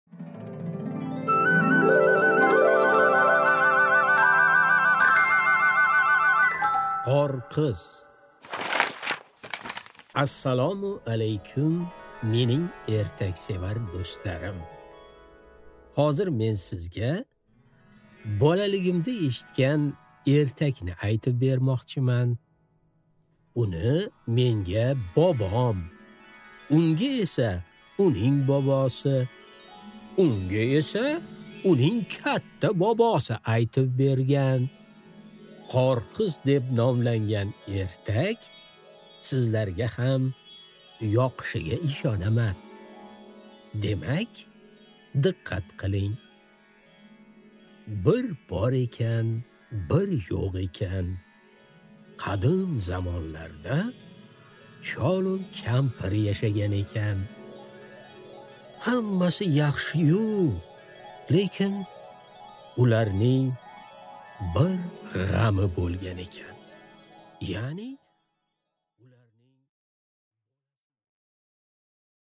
Аудиокнига Qorqiz